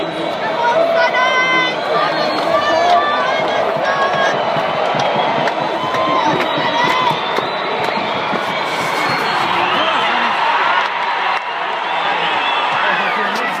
The game gets underway at Metricon Stadium